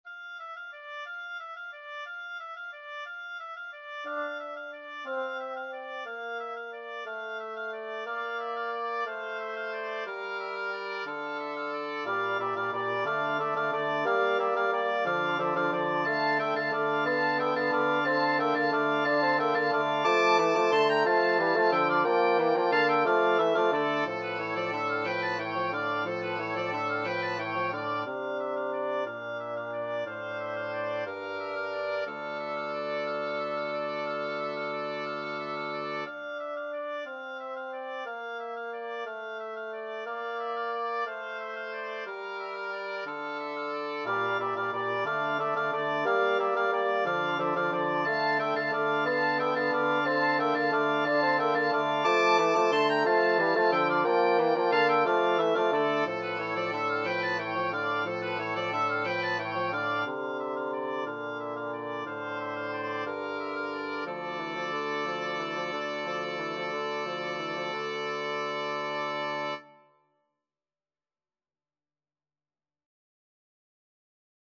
Oboe 1Oboe 2Bassoon 1Bassoon 2
3/4 (View more 3/4 Music)
D minor (Sounding Pitch) (View more D minor Music for Wind Quartet )
Wind Quartet  (View more Easy Wind Quartet Music)
Traditional (View more Traditional Wind Quartet Music)